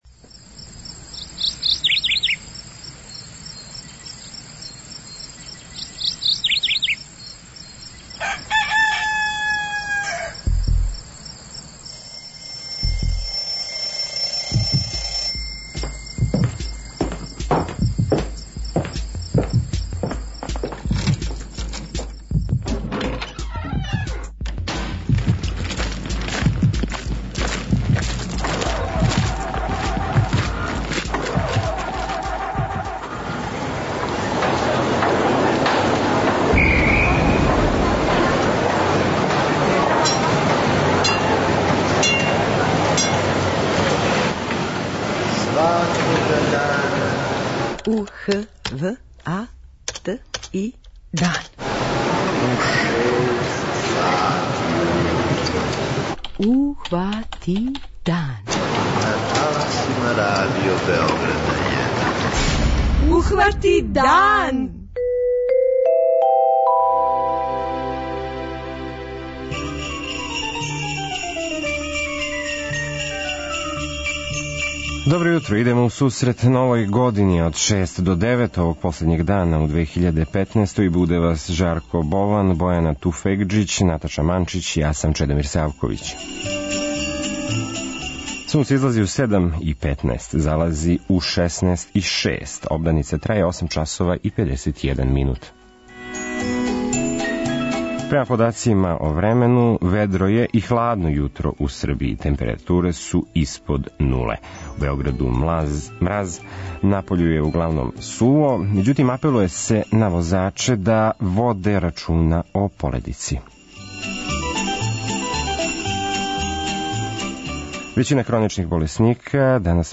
Наш први саговорник овог јутра је државни секретар у Министарству за рад Републике Словачке, Бранислав Ондруш.
преузми : 43.15 MB Ухвати дан Autor: Група аутора Јутарњи програм Радио Београда 1!